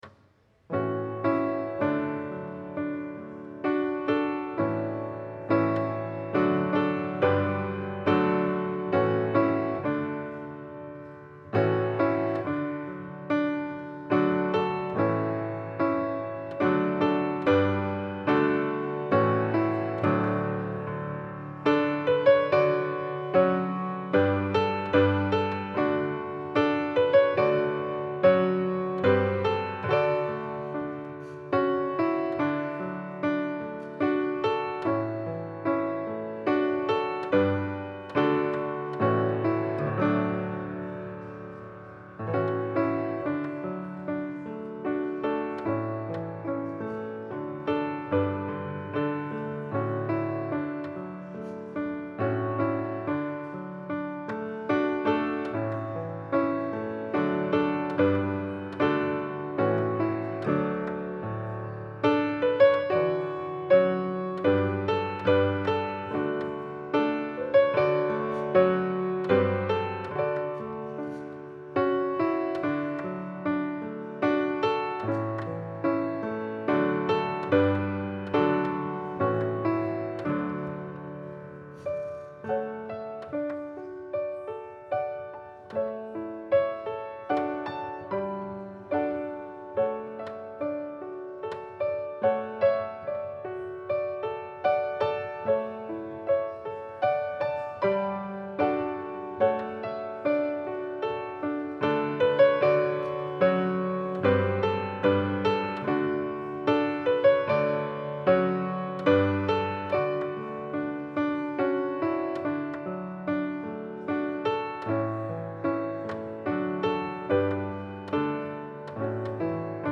Passage: Matthew 24: 1-14 Service Type: Sunday Service